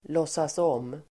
Uttal: [låtsas'åm: (el. lås-)]